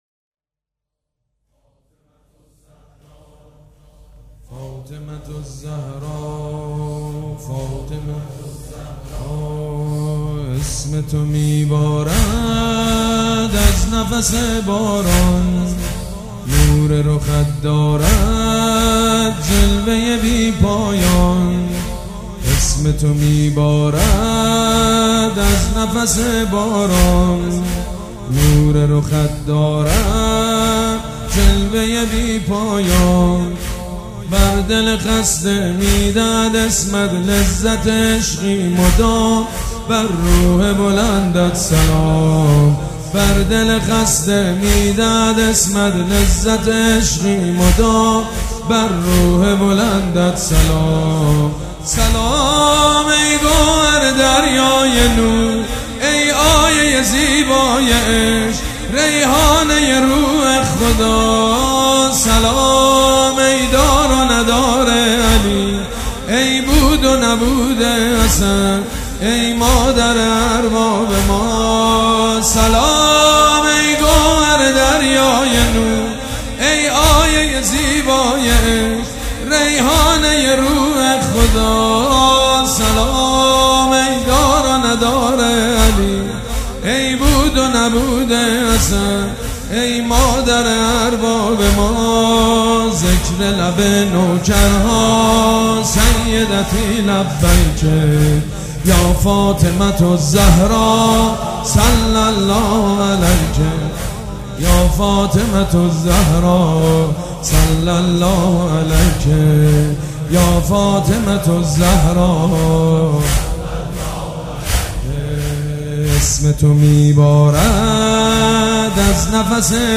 «فاطمیه 1396» زمینه: ذکر لب نوکر ها سیدتی لبیک